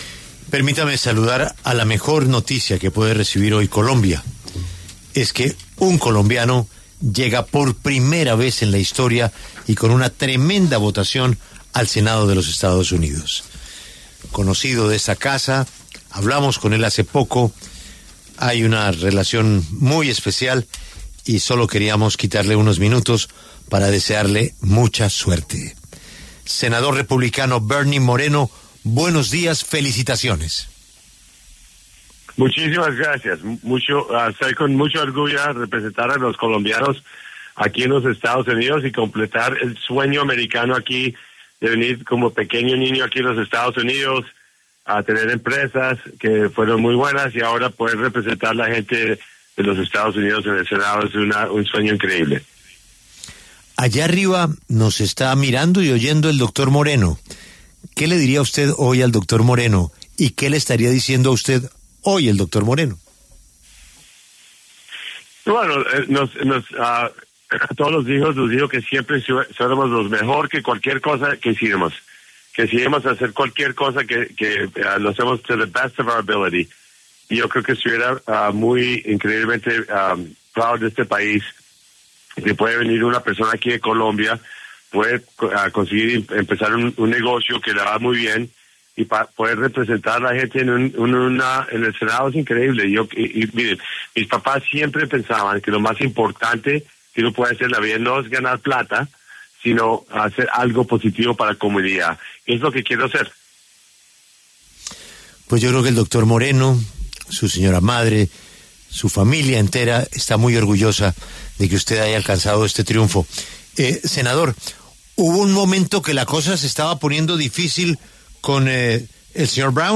Bernie Moreno, senador electo en Estados Unidos por el estado de Ohio, conversó con La W a propósito de cómo será sus relaciones con el Gobierno colombiano.